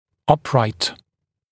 [‘ʌpraɪt][‘апрайт]прямостоящий, прямой, вертикальный; выравнивать в вертикальной плоскости, приводить в вертикальное положение